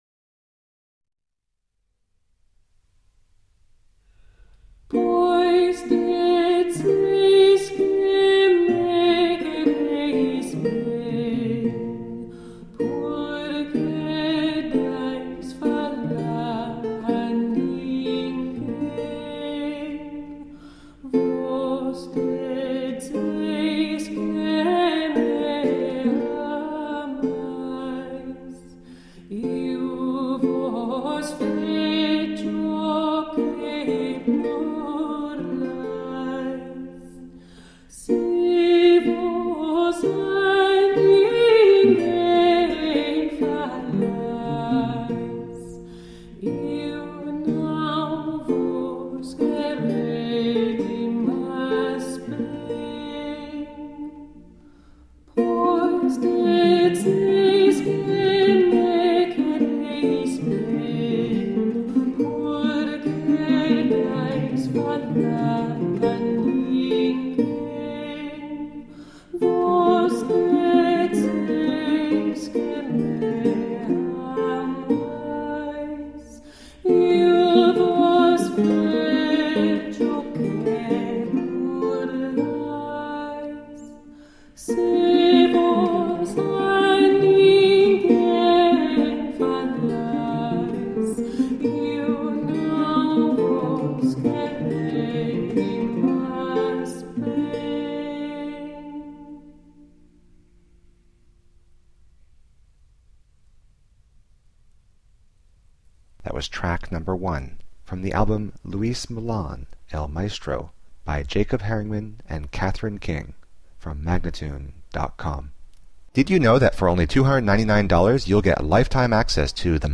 Renaissance songs.